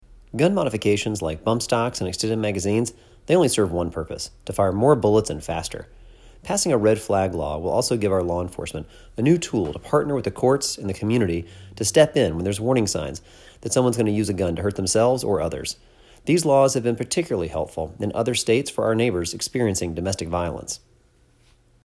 *Audio of Councilmember Allen’s quote attached